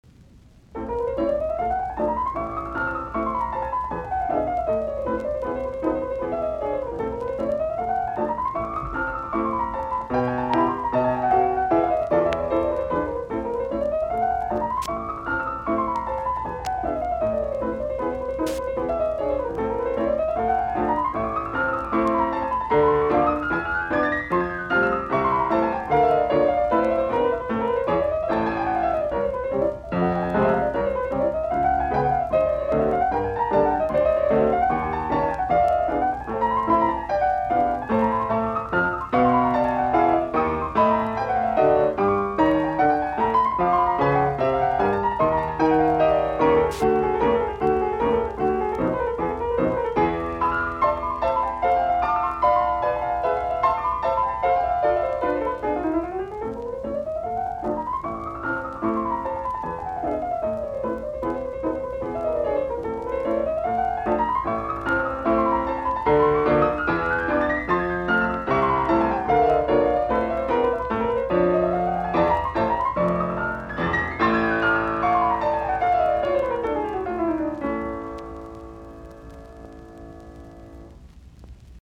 Etydit, piano, op10
Soitinnus: Piano.